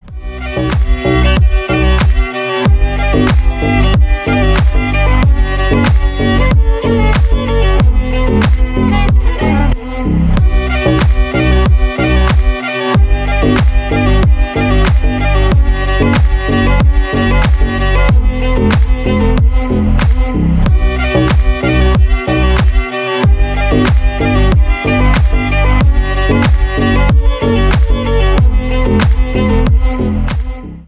Поп Музыка
клубные # без слов